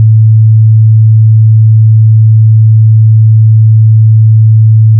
Sirena electrónica
Tono 03 - Continuo 110Hz.
Tono 03 - Continuo 110Hz..wav